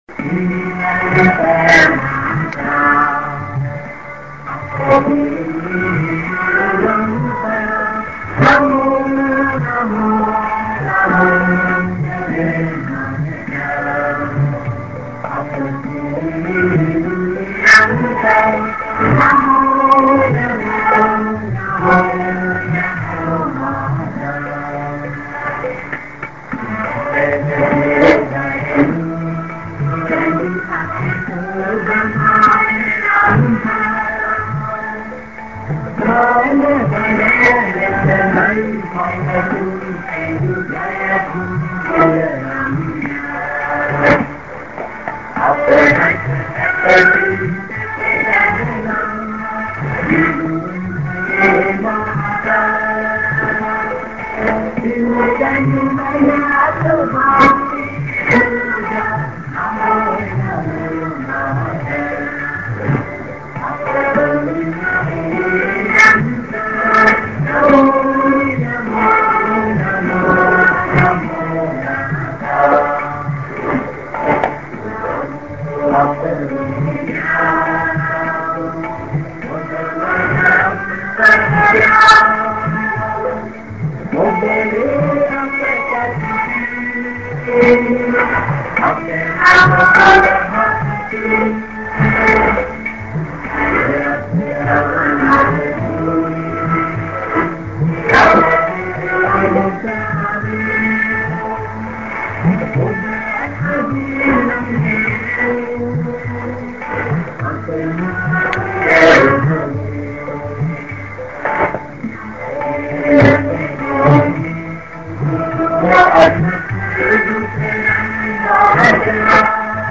b　End music(NA)